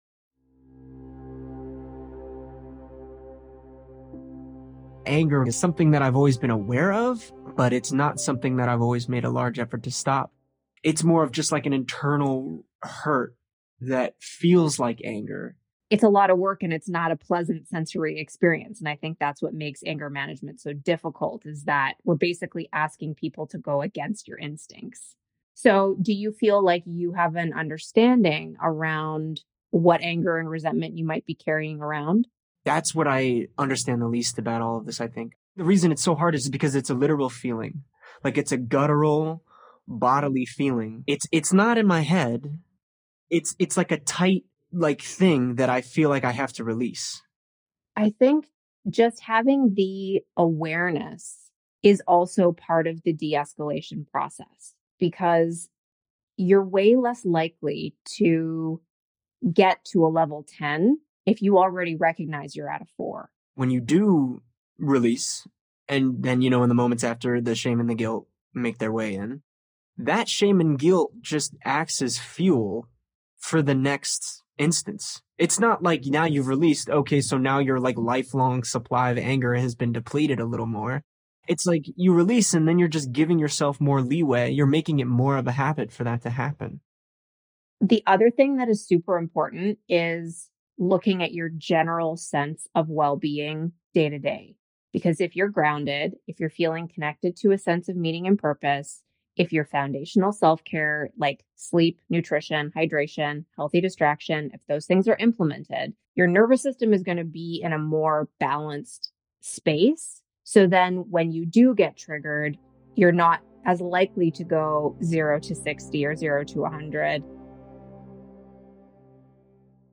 Meomind provides unlimited access to a library of 1000+ pre-recorded therapy sessions, featuring a handpicked team of licensed providers.
Black male in his late-20’s